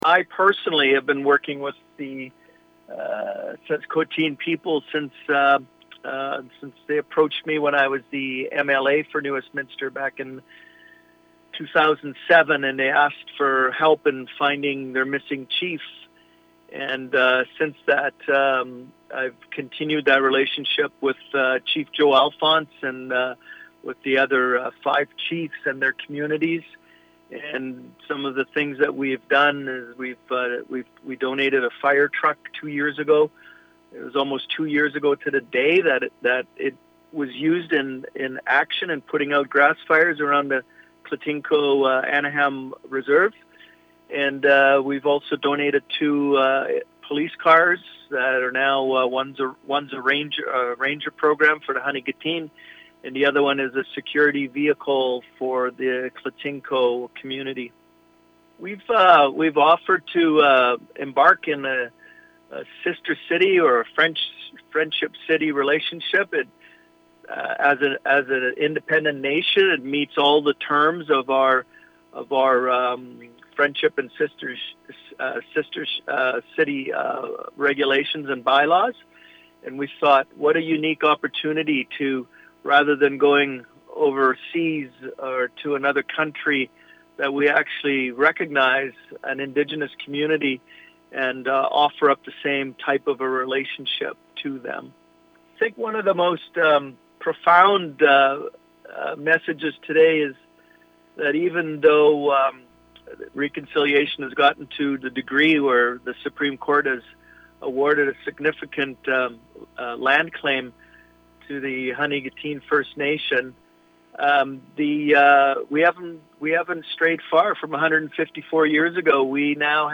(Editor’s Note: Listen to New Westminster City Councillor Chuck Puchmayr in the audio file below)